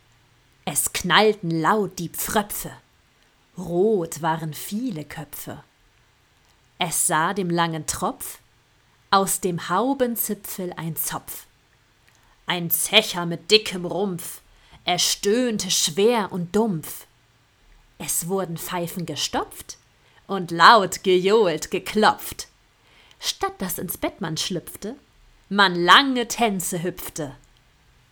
Dialekt hamburgisch
Phonetische Übung 2